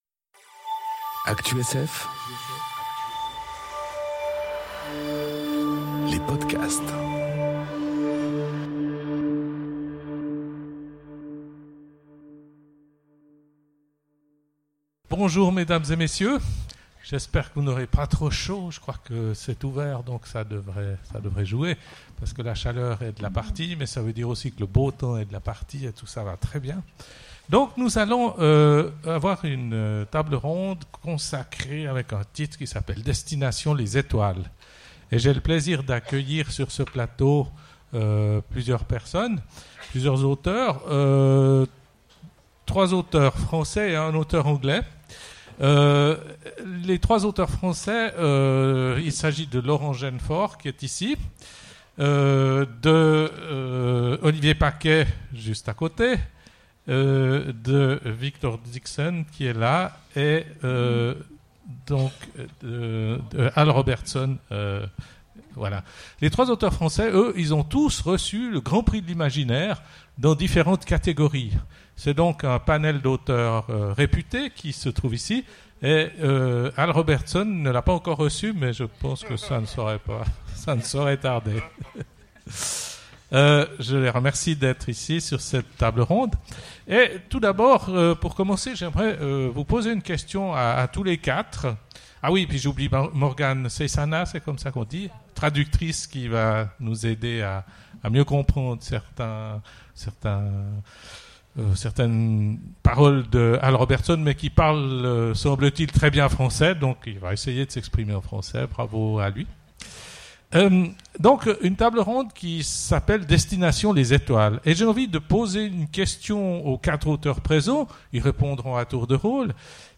Conférence Destination les étoiles... Ou juste le système solaire ? enregistrée aux Imaginales 2018